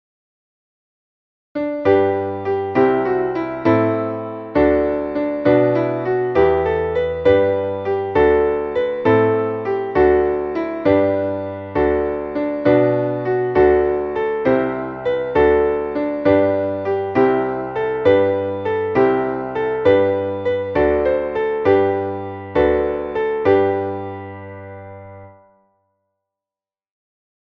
Traditionelles Winter-/ Weihnachtslied